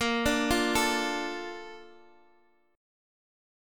A#M7 chord